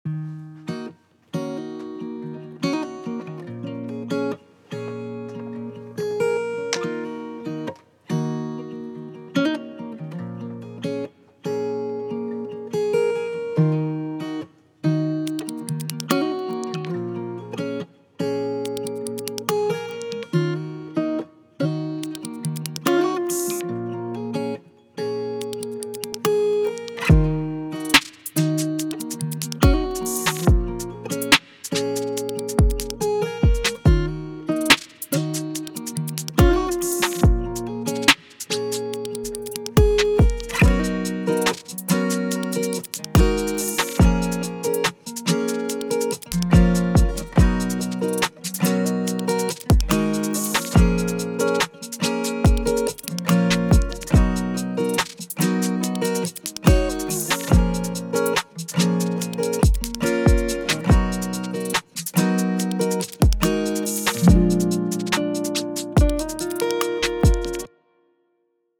R&B, Hip Hop, Alternative R&B, Soul
Ebmin